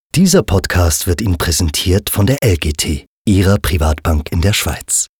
LGT_Preroll.mp3